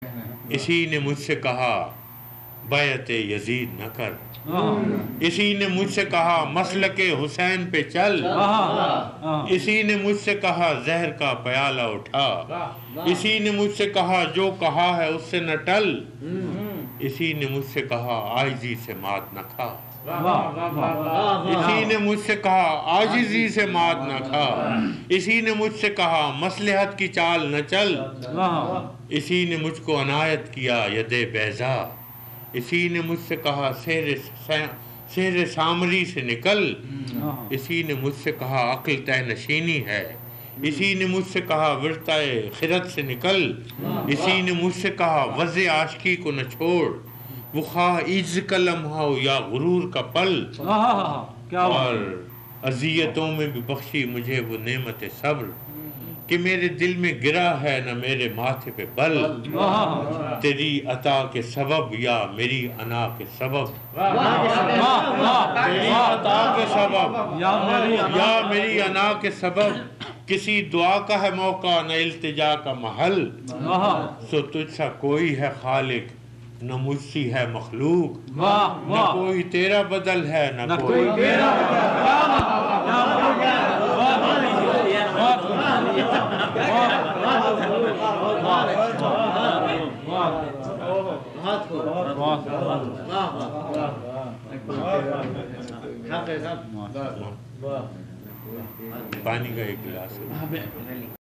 It is posted on youtube as a recitation by faraz at a private gathering.
af-qaseeda-qalam-41-49-baiat-e-yaziid-na-kar-faraz.mp3